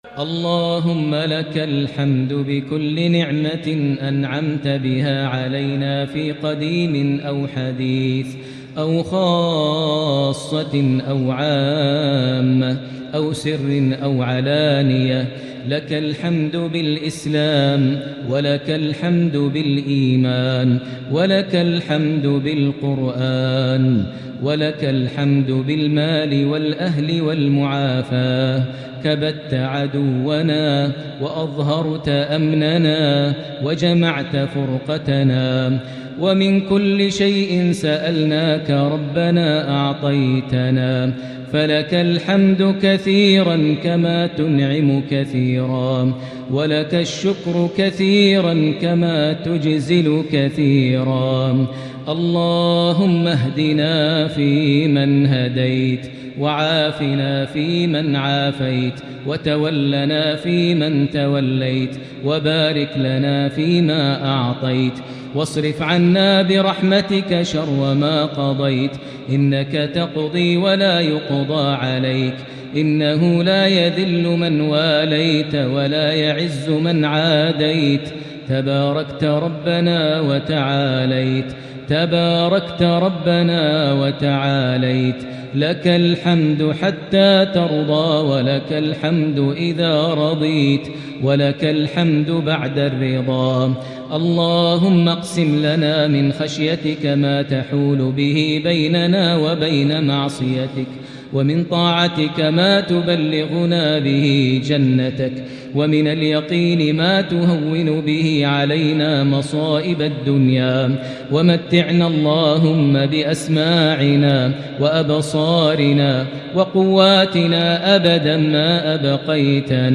دعاء القنوت ليلة 28 رمضان 1442هـ | Dua for the night of 28 Ramadan 1442H > تراويح الحرم المكي عام 1442 🕋 > التراويح - تلاوات الحرمين